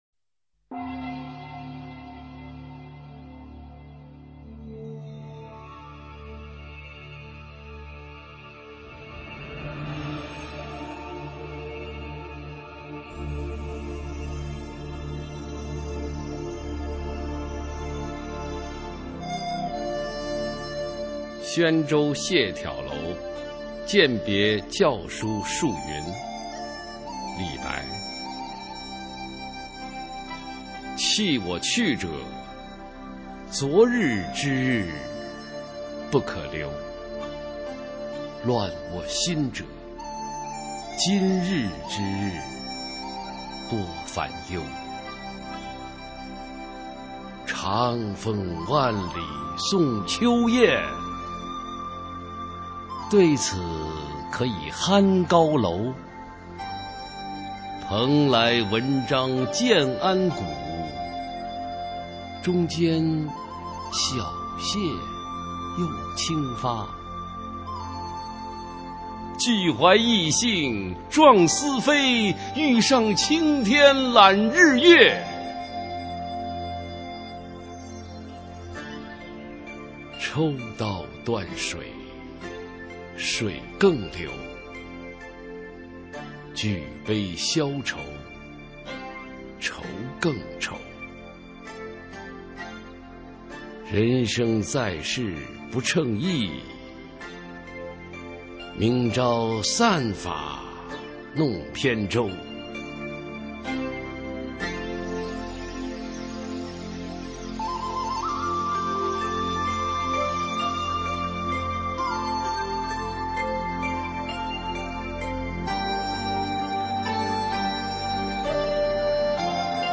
普通话美声欣赏：宣州谢眺楼饯别校书叔云　/ 佚名